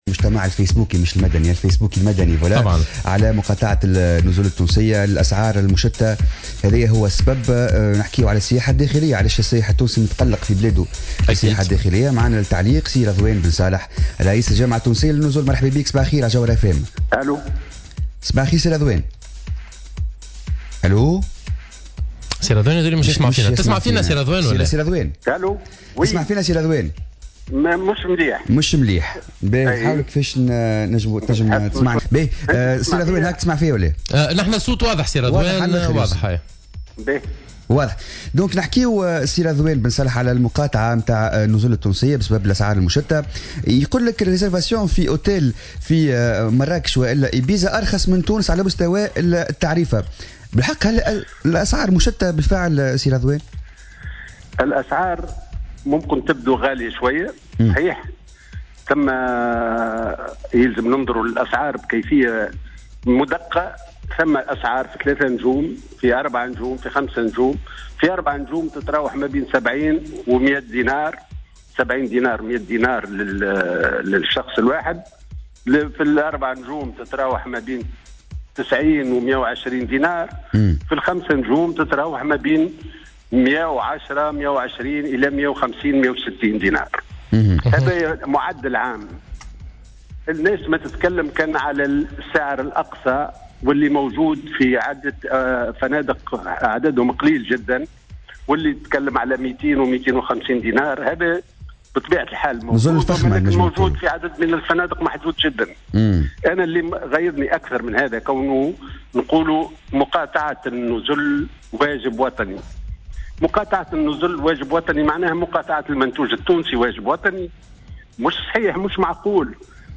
في مداخلة له على الجوهرة "اف ام"